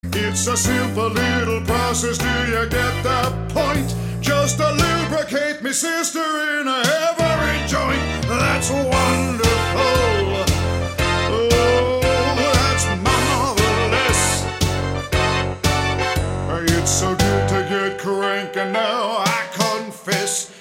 Song Samples: